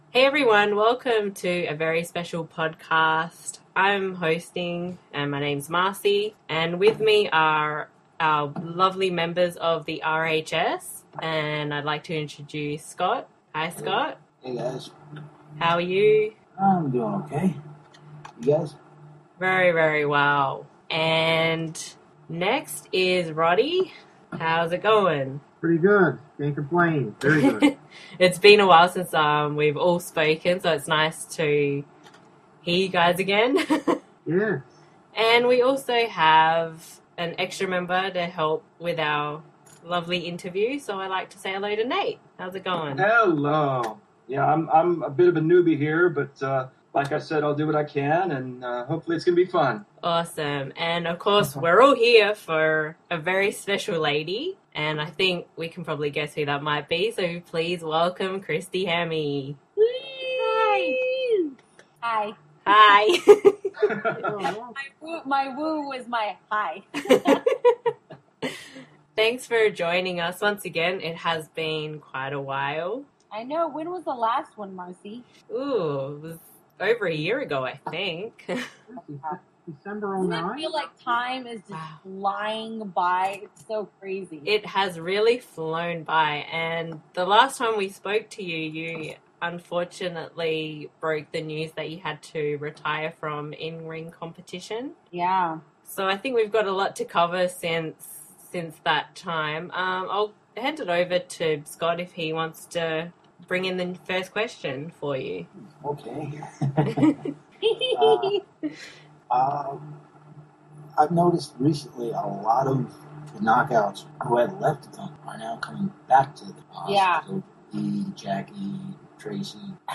Official RHS Podcast – New Interview with Christy Hemme
rhs-christy-hemme-interview-aug-2011.mp3